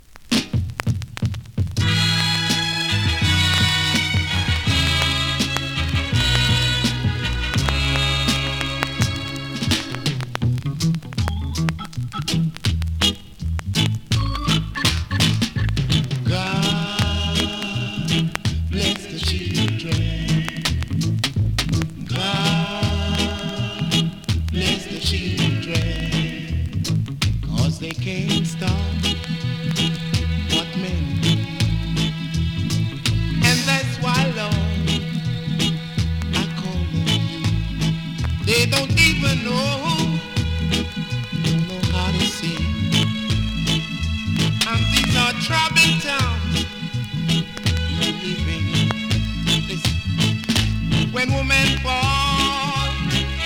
2026!! NEW IN!SKA〜REGGAE
スリキズ、ノイズそこそこあります。